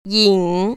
[yĭng] 잉  ▶